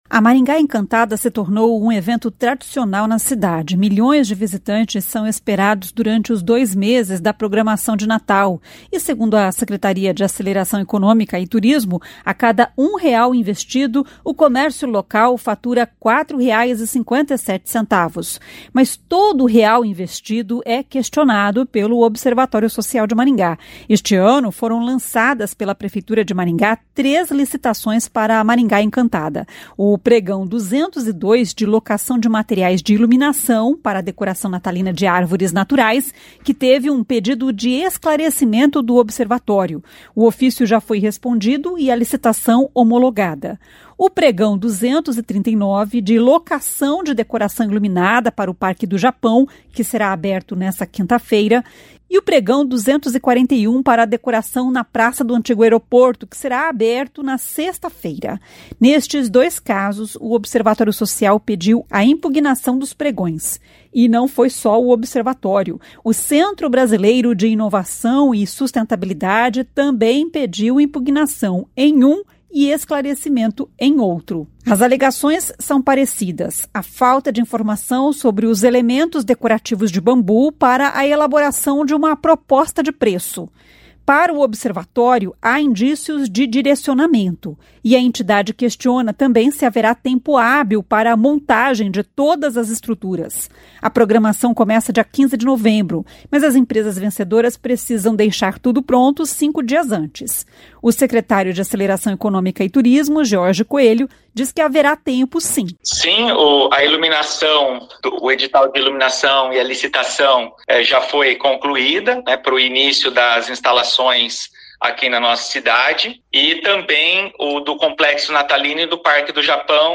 O secretário de Aceleração Econômica e Turismo, George Coelho, diz que haverá tempo sim.